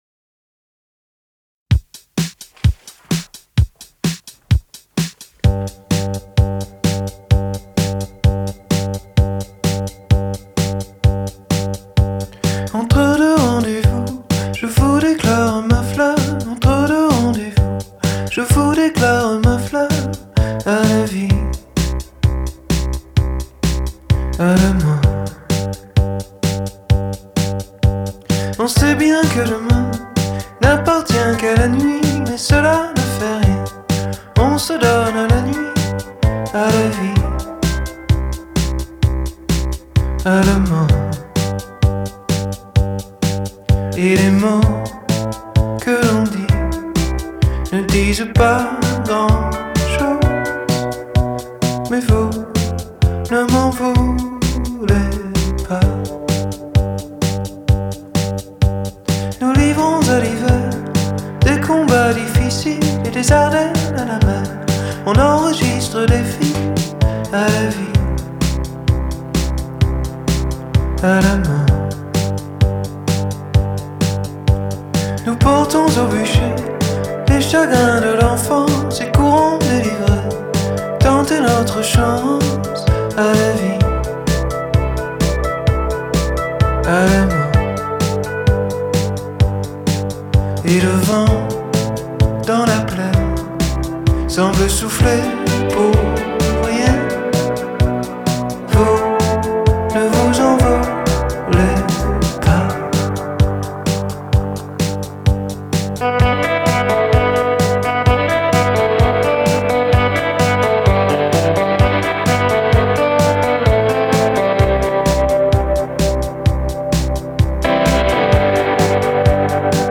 Genre: World, French Pop, Indie, Chanson